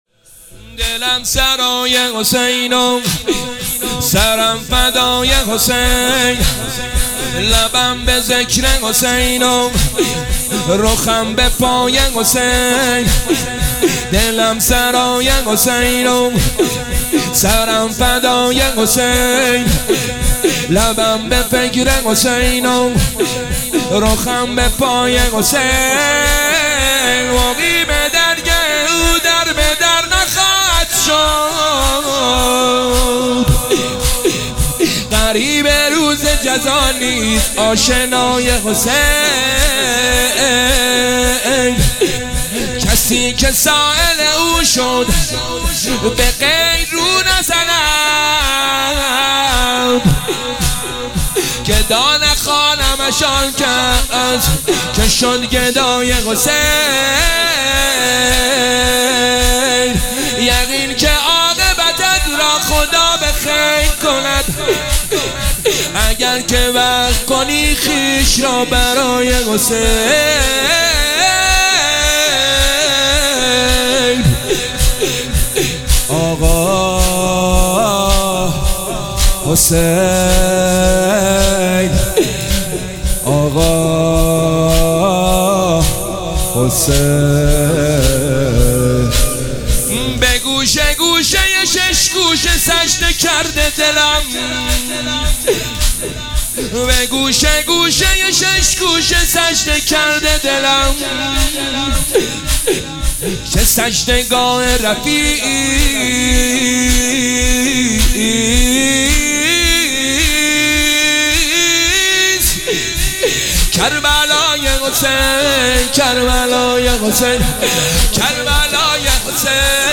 شب 23 ماه رمضان 1446